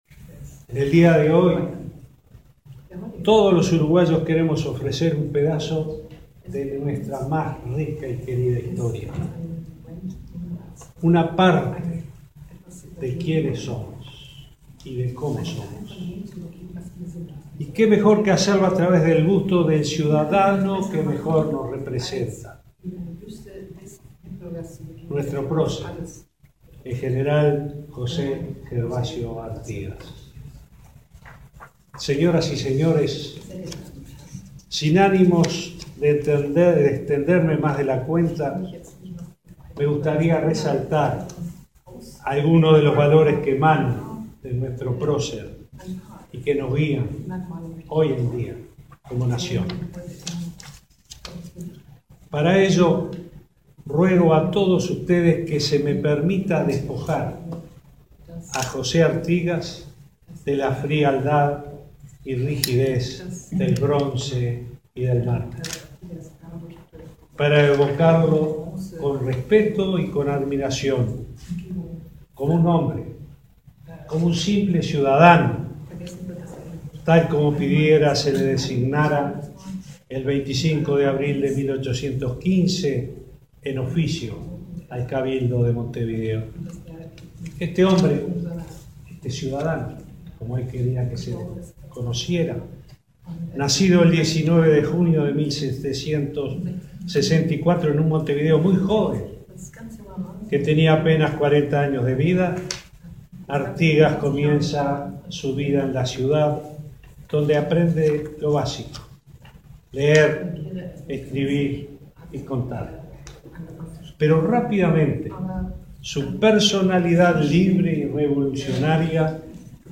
El Presidente Vázquez evocó este viernes la figura de Artigas al presidir la ceremonia de donación de un busto de nuestro prócer a la Alcaldía de Hamburgo. En ese marco, hizo un breve repaso de su trayectoria y dijo que su grandeza de alma y su compromiso con los más humildes lo que lo hace trascender.